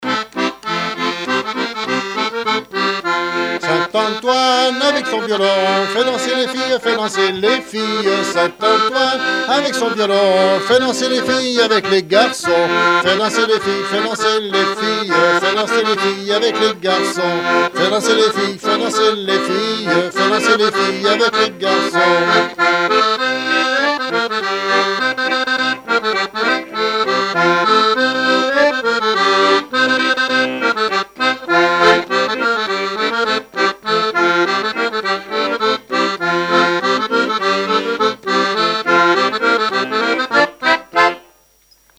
Mémoires et Patrimoines vivants - RaddO est une base de données d'archives iconographiques et sonores.
Vendée
Chants brefs - A danser
danse : scottich trois pas
Pièce musicale inédite